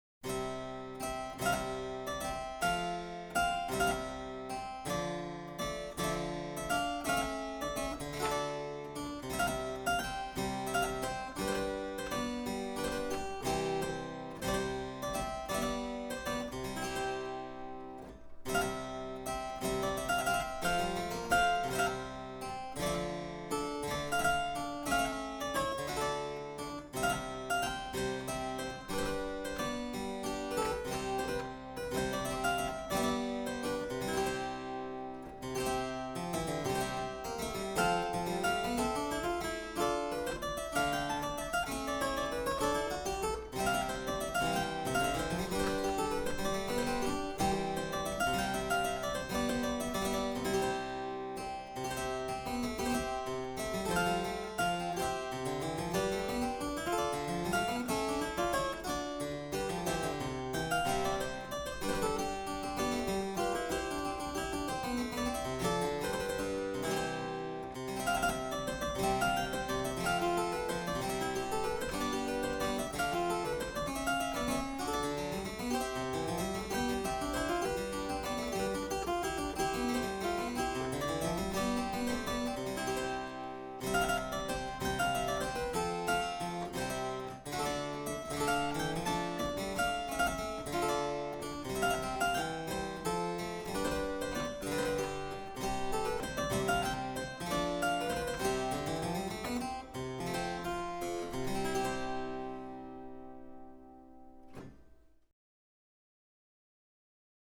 KING CHARLES II’s VIRGINALS - THE COBBE COLLECTION